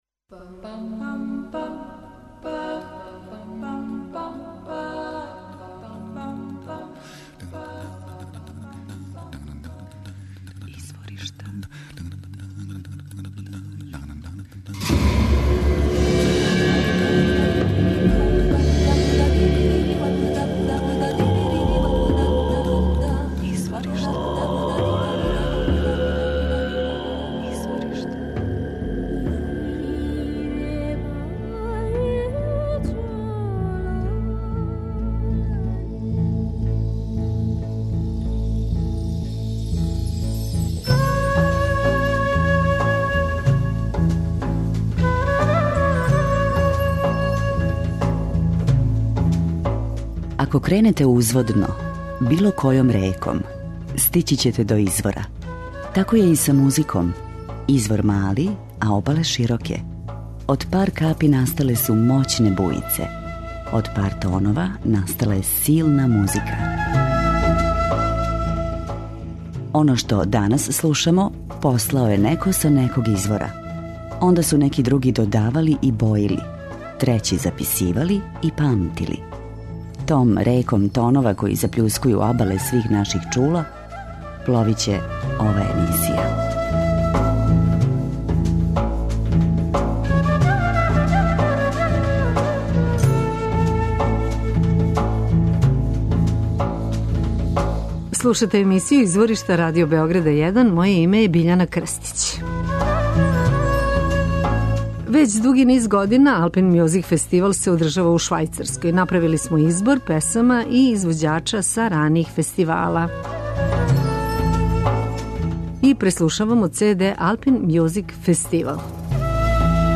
Јодловање је мелодија без текста, кратки узвици који имају различито значење, као светлосни сигнали, као махање заставицама, као морзеова азбука... комуникација између суседа који су удаљени, разговор с брда на брдо.
преузми : 28.40 MB Изворишта Autor: Музичка редакција Првог програма Радио Београда Музика удаљених крајева планете, модерна извођења традиционалних мелодија и песама, културна баштина најмузикалнијих народа света, врели ритмови...